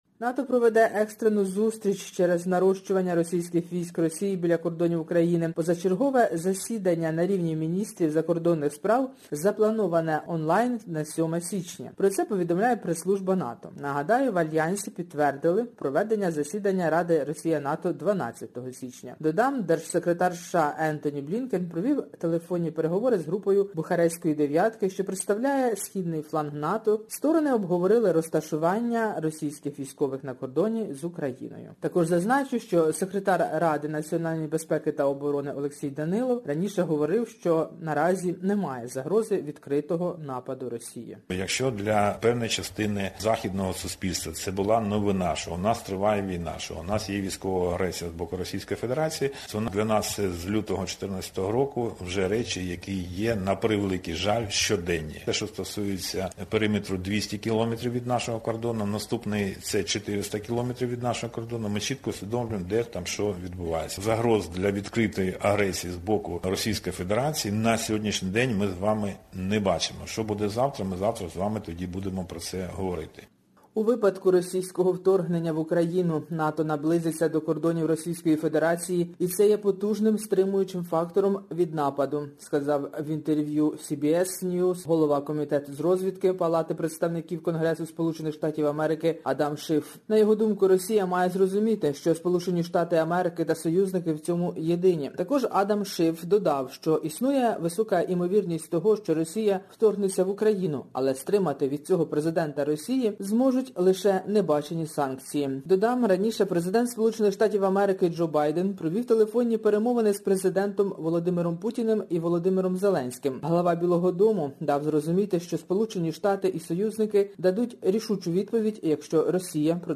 Добірка новин із наших земель рідних.